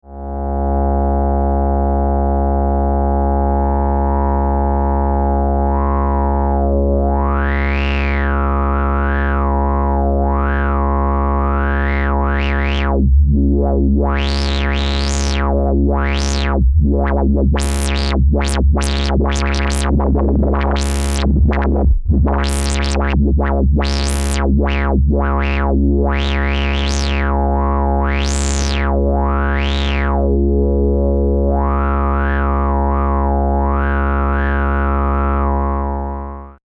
CVランダム・アウトをA-122のフィルケンシーへ接続 (波形はA-111矩形波を使用)